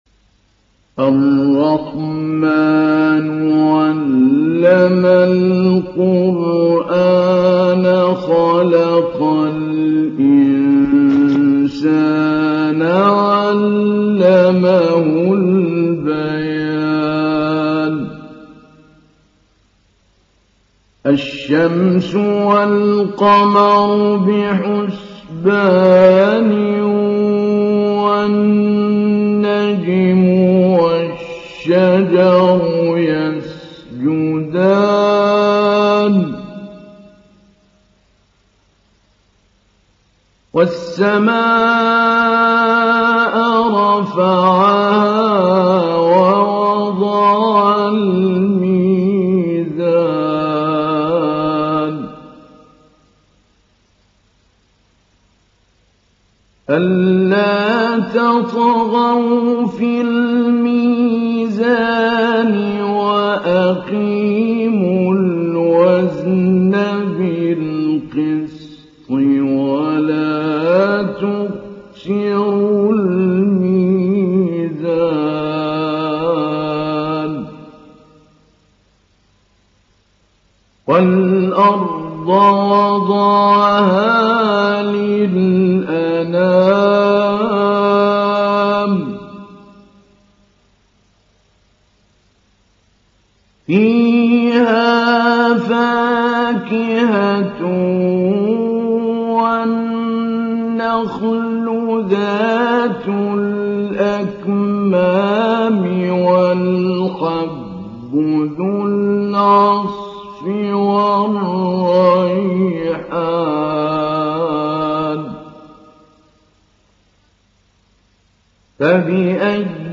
تحميل سورة الرحمن mp3 بصوت محمود علي البنا مجود برواية حفص عن عاصم, تحميل استماع القرآن الكريم على الجوال mp3 كاملا بروابط مباشرة وسريعة
تحميل سورة الرحمن محمود علي البنا مجود